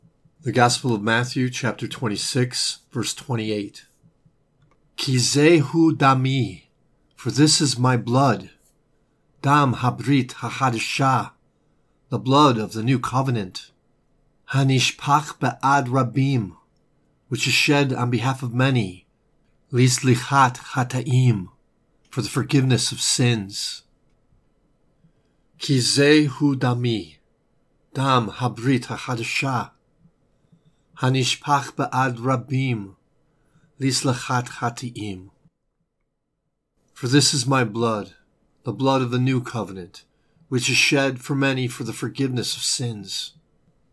Hebrew Lesson: